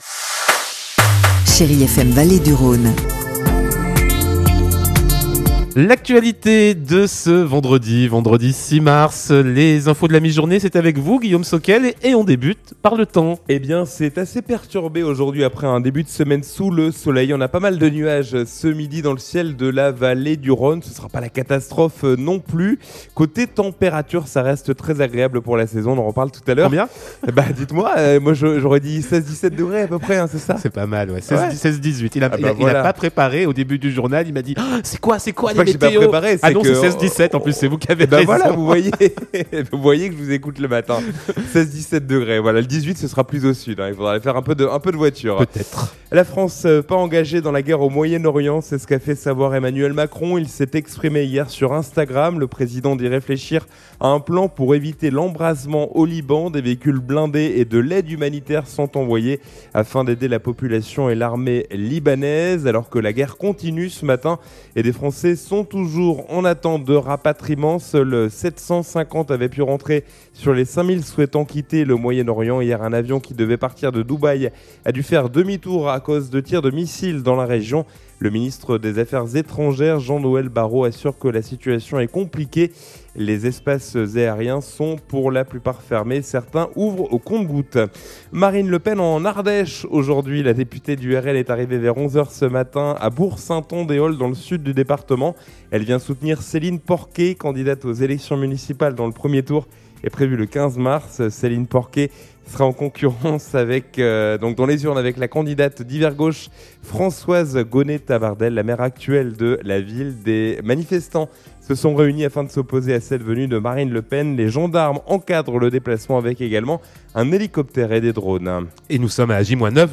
Vendredi 6 mars : Le journal de midi